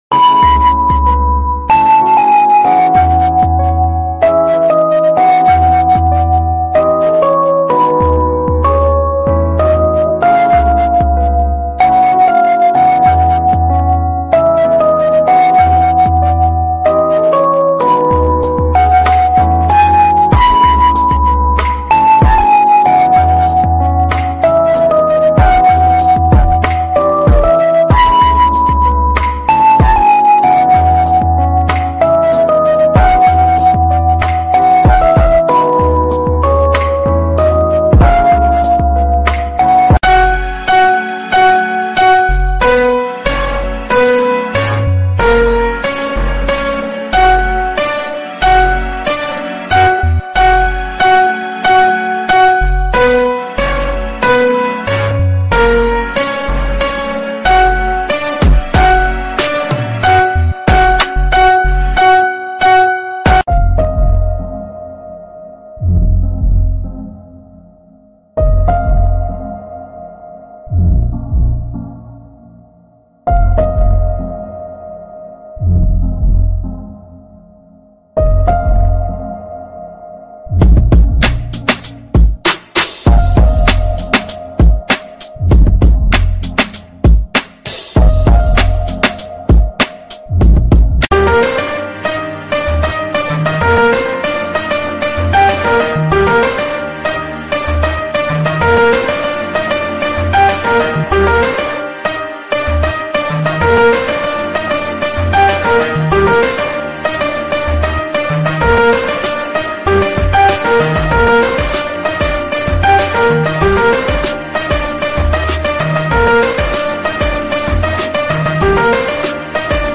Peaceful Relaxing Music